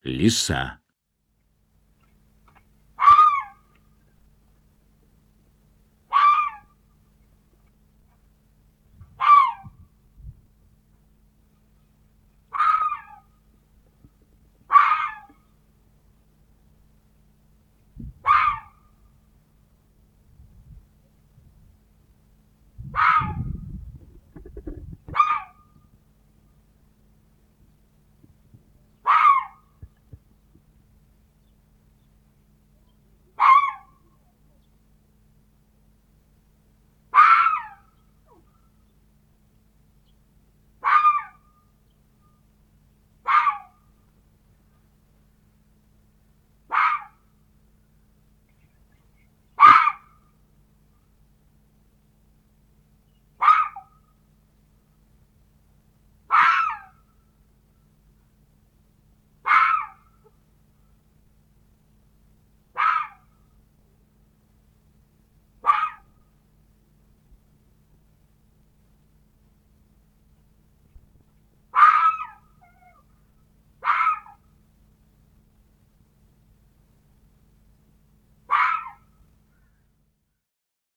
На этой странице собраны разнообразные звуки лисы: от реалистичных рычаний и тявканий до весёлых детских песенок.
Голос лисы для маленьких слушателей